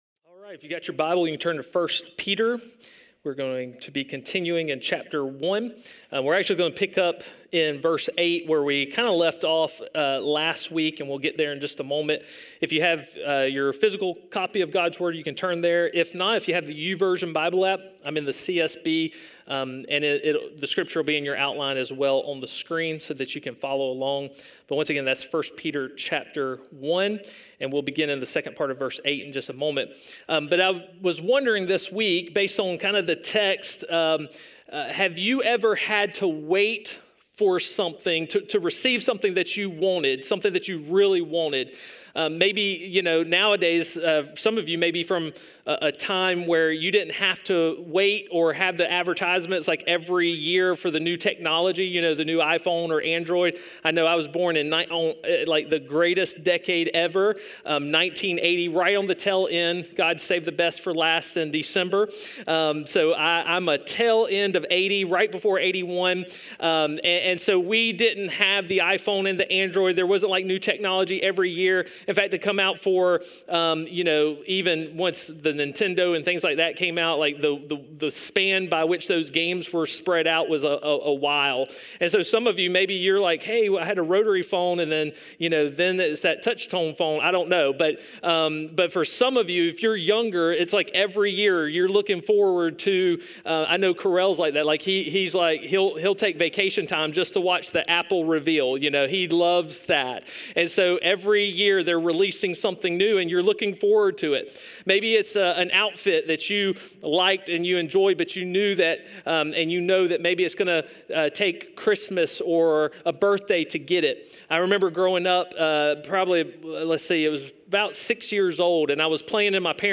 A message from the series "Foreigners."